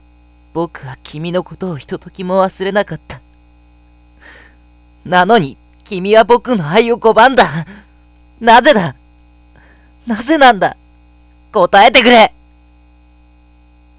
今回…マイクを買い換えたところ質がグレードダウン
音質は少々悪いようですがそれは皆様の気合いで